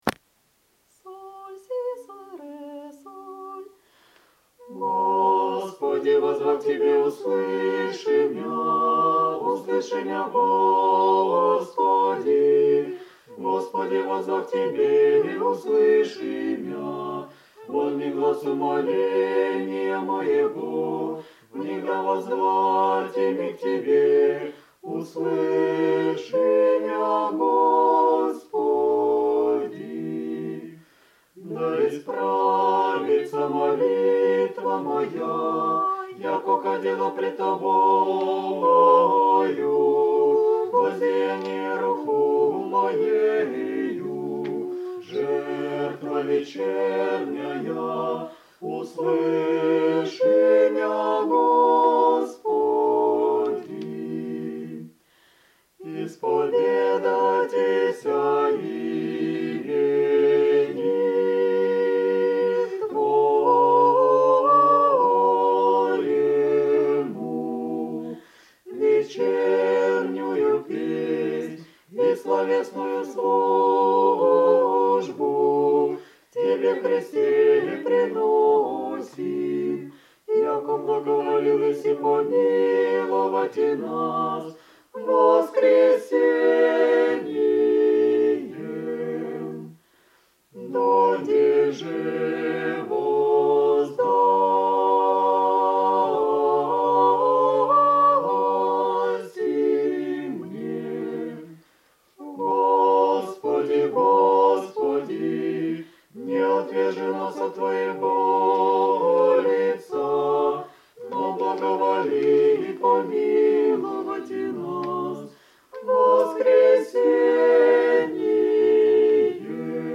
8stihirnuy_kvartet.MP3.mp3